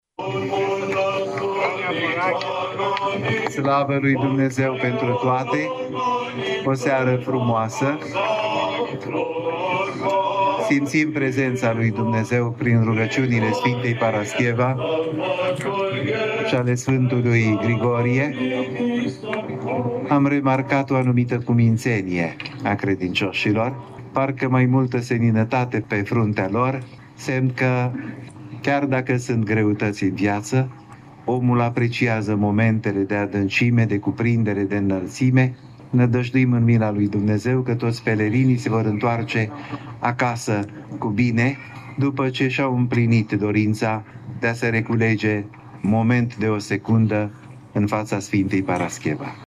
Înalt Prea Sfințitul Teofan a vorbit despre profunzimea momentului: ”Slavă Lui Dumnezeu pentru toate! O seară frumoasă! Simțim prezența Lui Dumnezeu prin rugăciunile Sfintei Parascheva și ale Sfântului Grigorie.
13-oct-rdj-8-IPS-Teofan.mp3